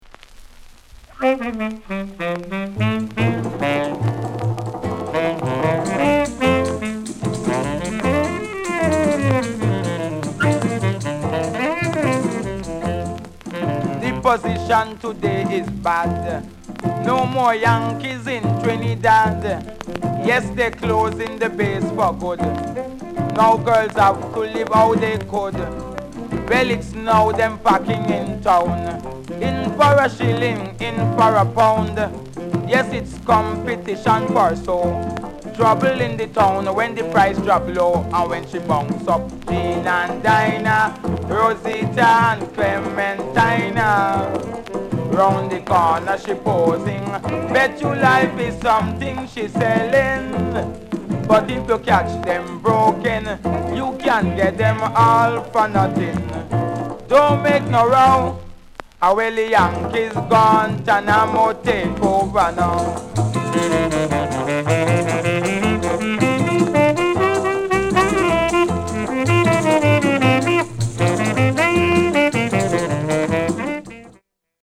SOUND CONDITION A SIDE VG(OK)
RARE CALYPSO